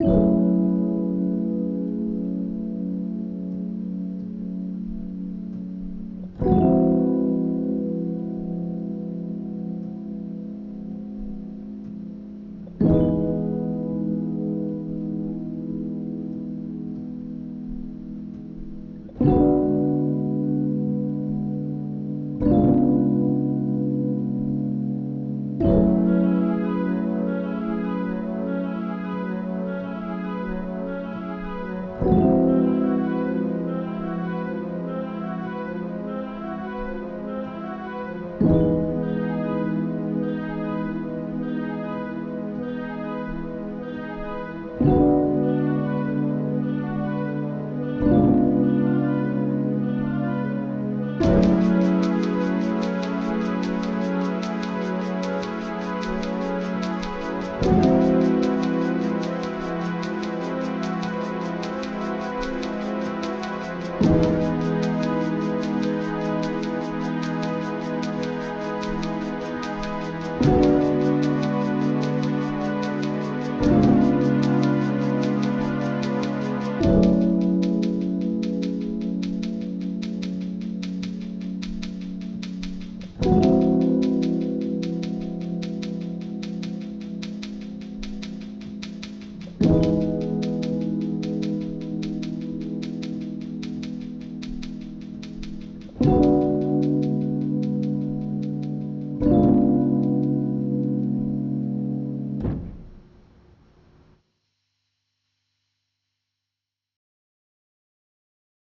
Lo-Fi